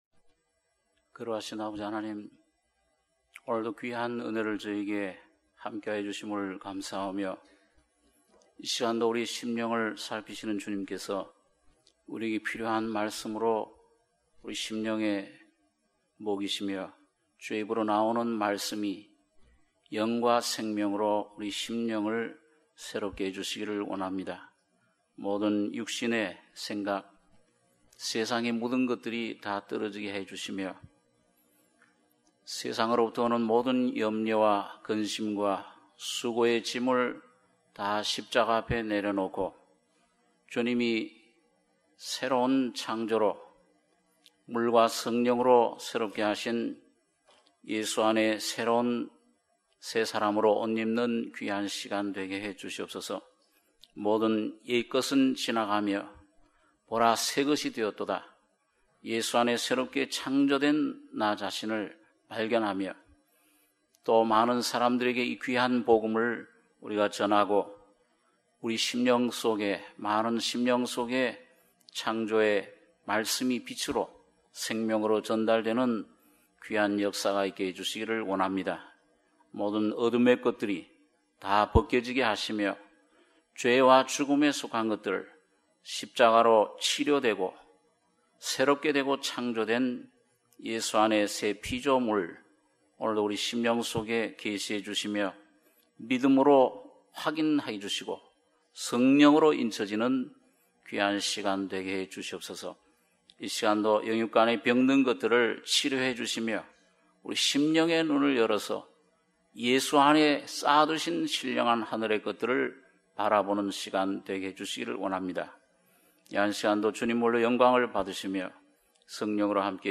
수요예배 - 이사야 17장 1절~11절